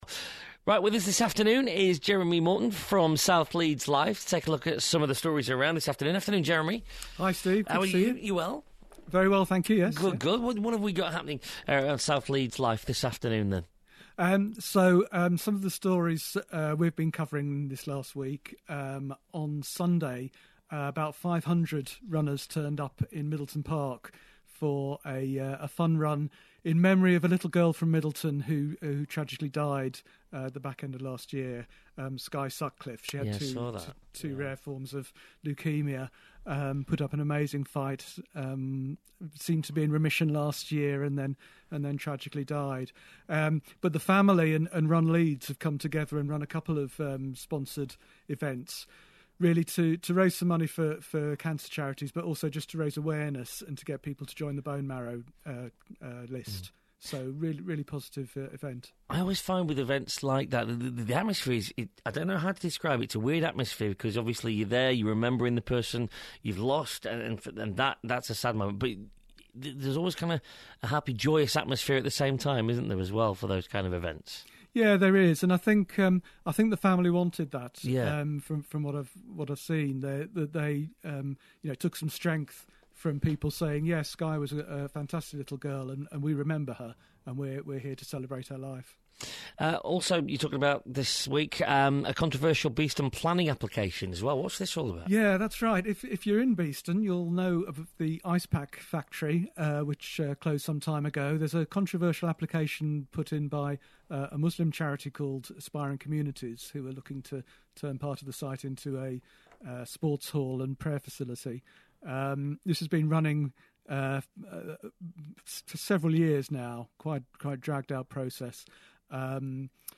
Paper review on BBC Radio Leeds